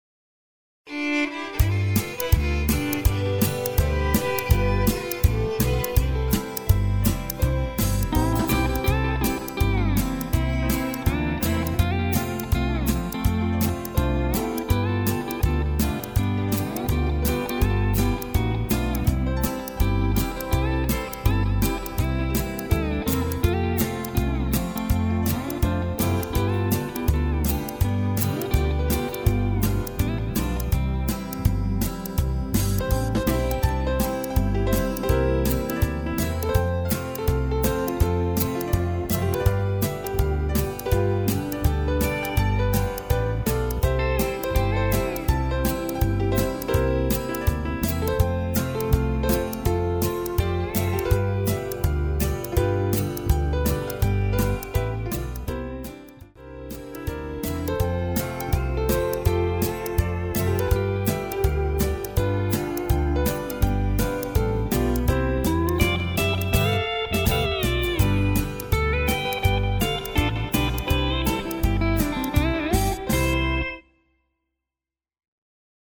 Round Dance
Two Step